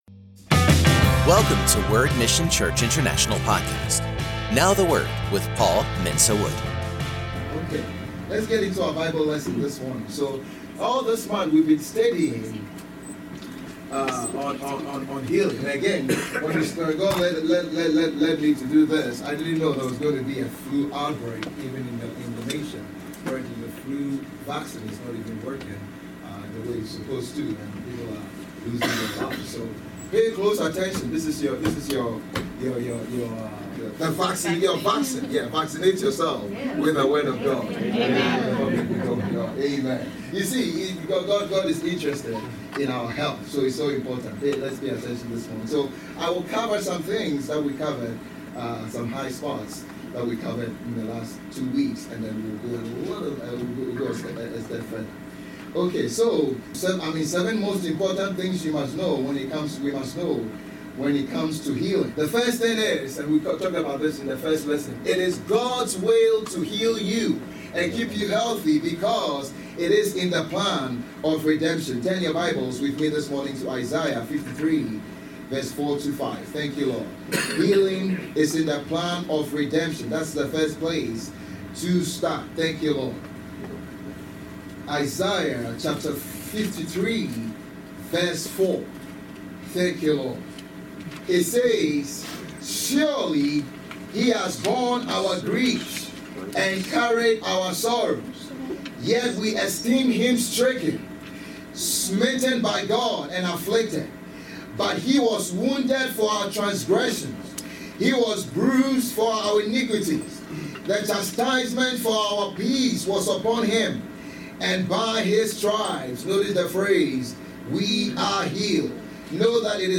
Post category: Sermon